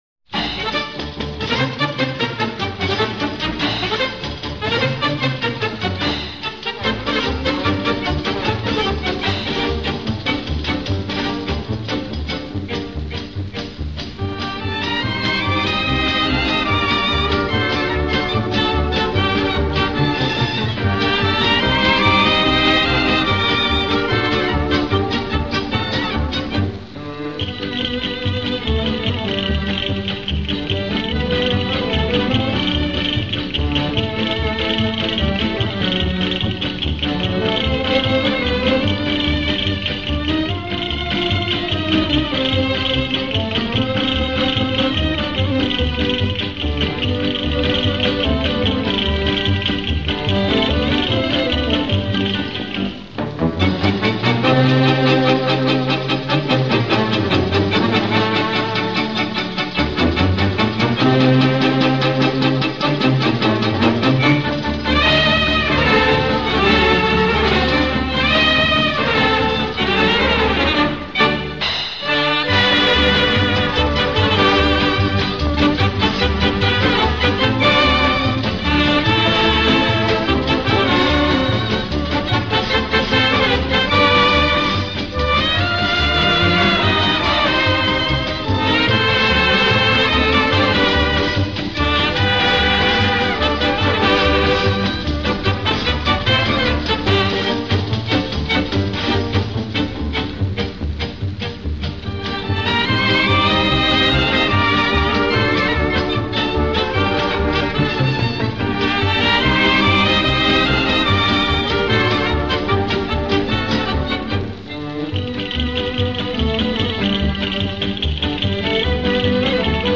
Пасадобль